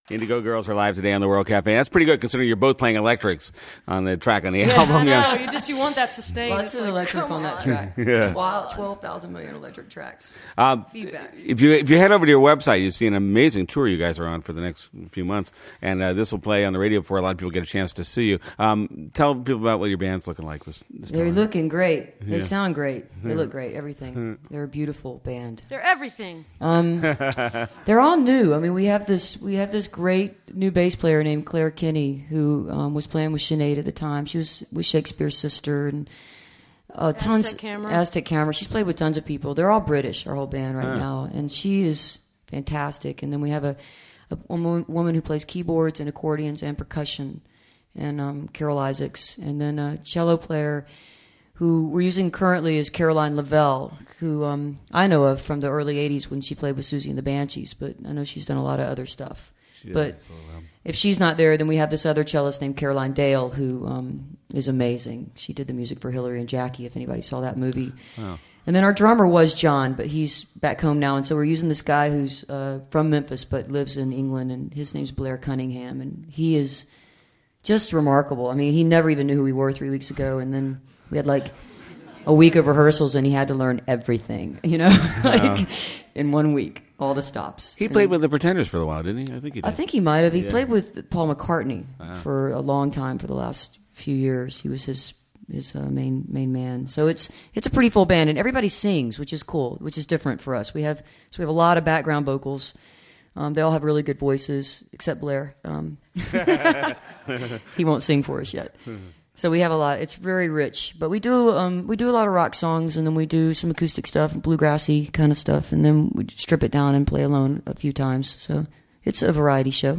lifeblood: bootlegs: 1999-10-05: world cafe recording session at inderay studios - philadelphia, pennsylvania
07. interview (2:58)